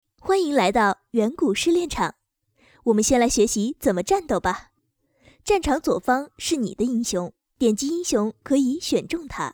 女声
英雄联盟角色模仿-35龙女